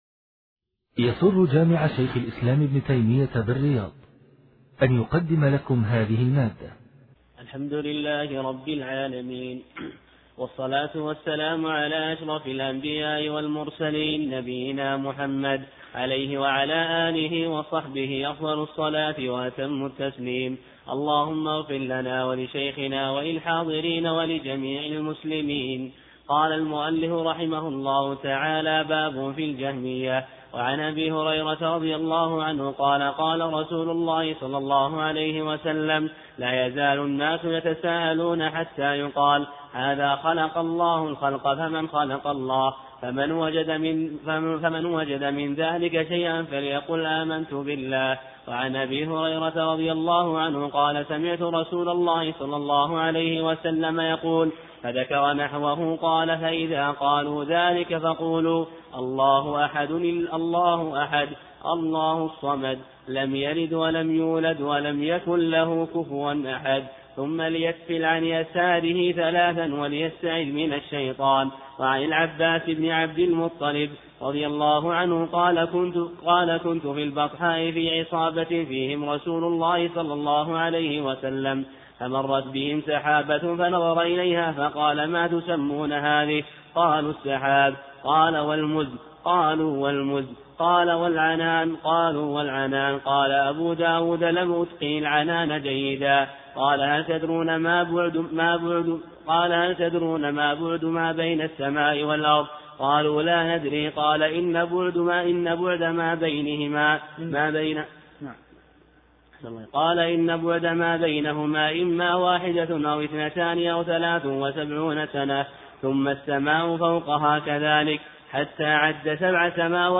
6 - الدرس السادس